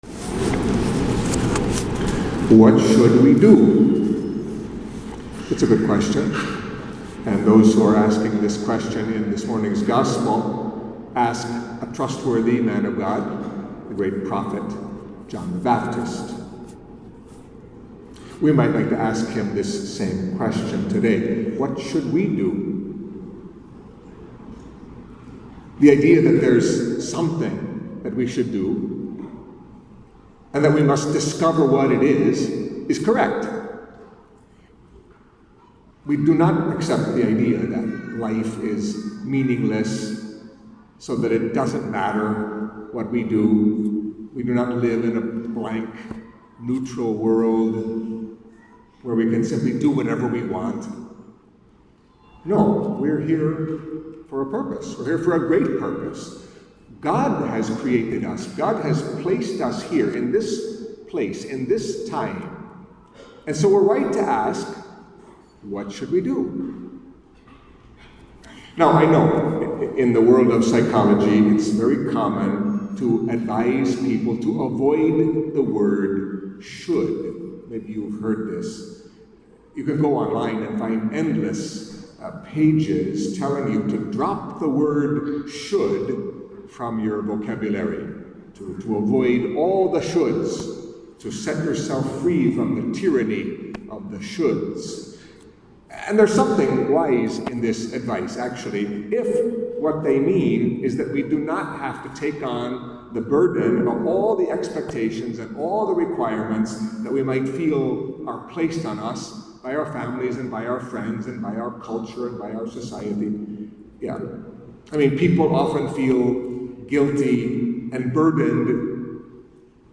Catholic Mass homily for the Third Sunday of Advent